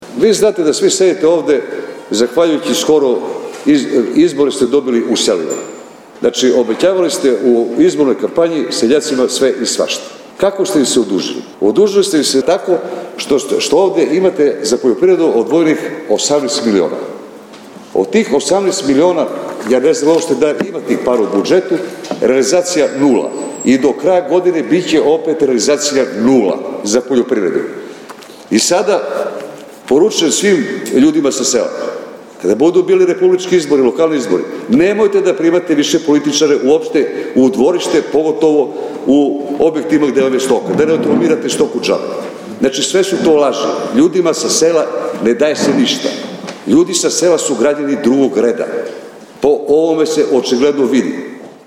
Na sednici Skupštine grada održanoj u sredu u Zaječaru odbornici su razmatrali Izveštaj o izvršenju budžeta za prvih devet meseci ove godine.
Diskutujući o toj temi odbornik Miodrag Simonović, obraćajući se odbornicima većine, rekao je da su u odborničkim klupama zahvaljujući pobedi u selima na poslednjim izborima.